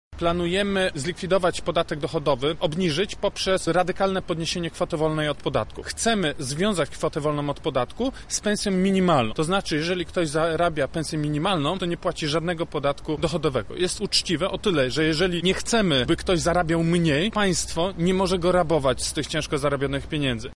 O tym jakie zmiany Konfederacja uważa za kluczowe mówi Jakub Kulesza, poseł oraz lider lubelskiej listy KW Konfederacja Wolność i Niepodległość: